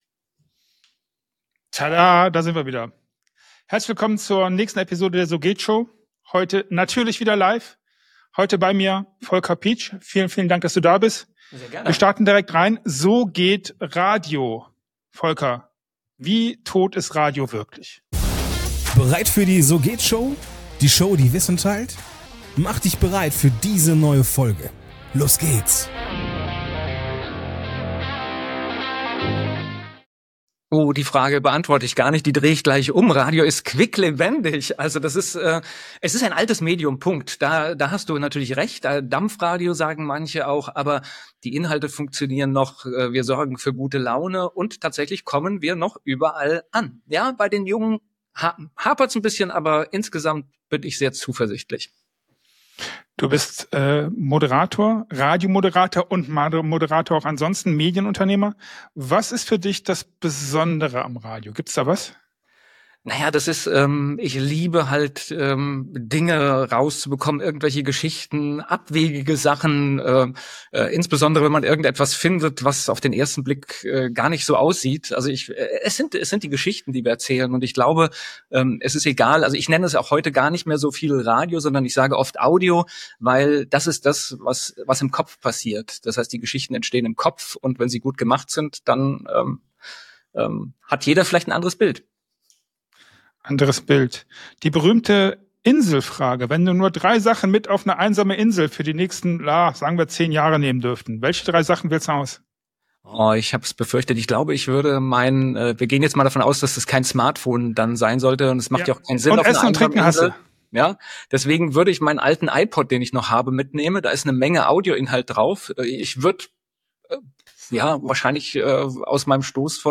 Du bekommst keinen glattgebügelten Medientalk.